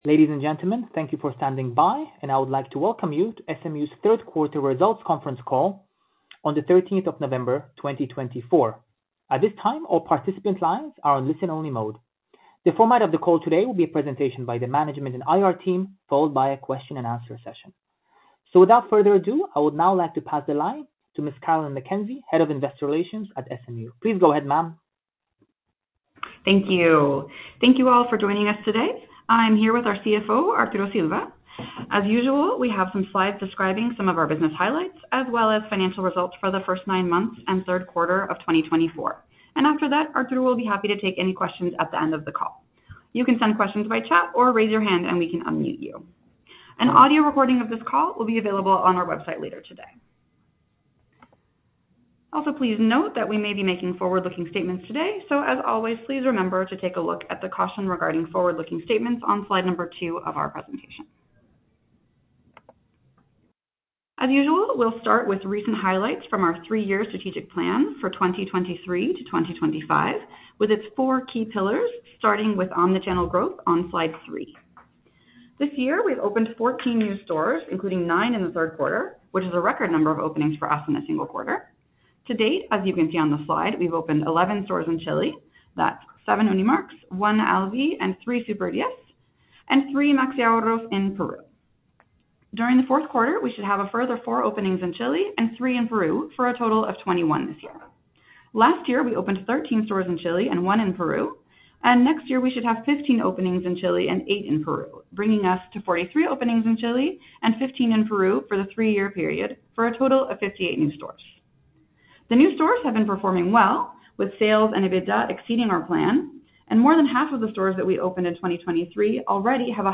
Conference_Call_Audio_3T24.mp3